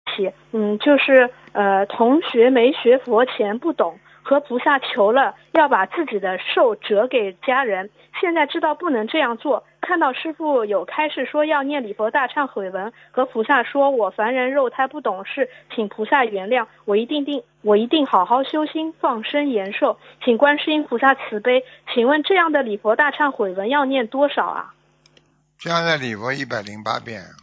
目录：☞ 2019年10月_剪辑电台节目录音_集锦